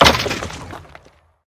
Minecraft Version Minecraft Version snapshot Latest Release | Latest Snapshot snapshot / assets / minecraft / sounds / mob / wither_skeleton / death2.ogg Compare With Compare With Latest Release | Latest Snapshot